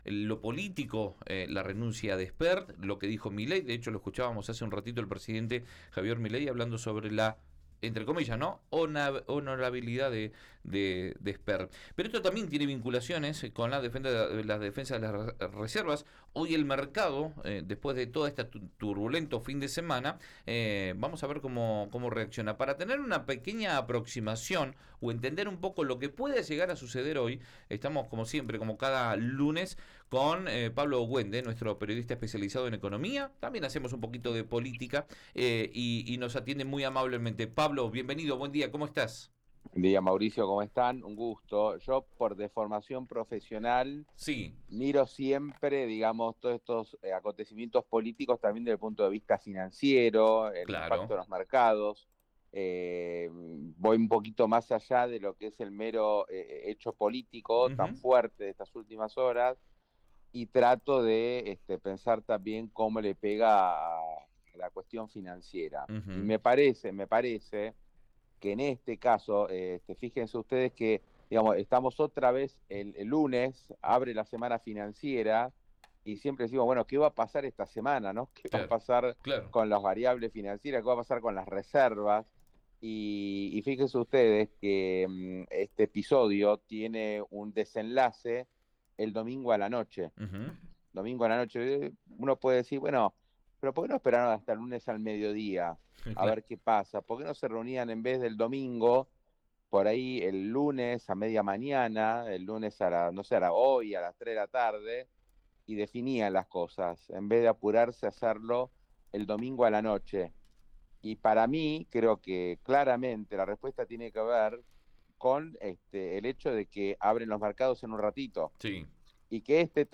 Escuchá lo que dijo el especialista en Río Negro Radio.